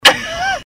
Laugh 14